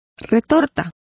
Complete with pronunciation of the translation of retort.